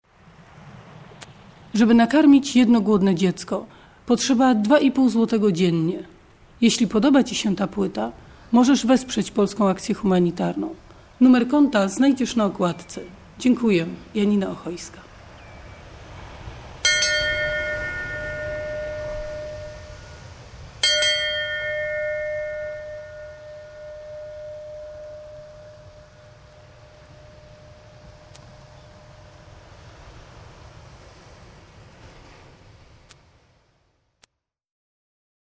Znana wszystkim Janina Ochojska - twórca PAH oraz akcji Pajacyk również
oddała swój głos na tę płytę ;-))) zresztą sami usłyszycie! ;-))